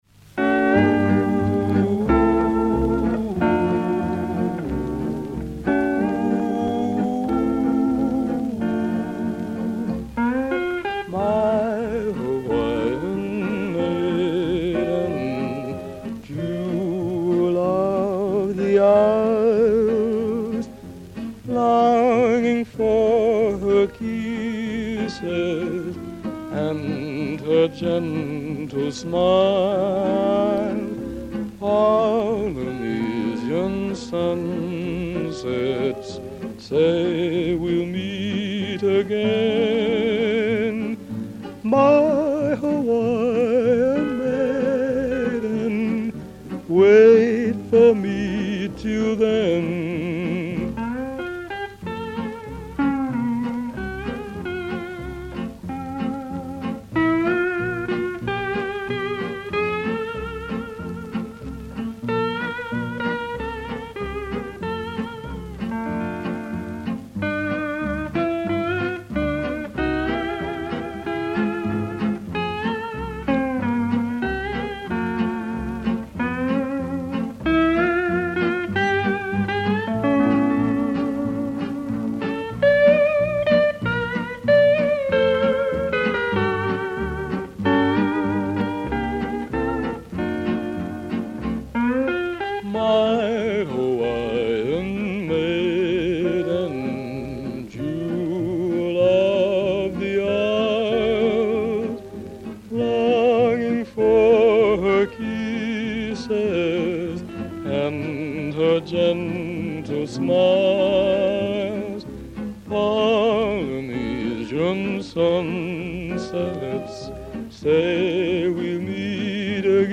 TAGGED: Hawaiian music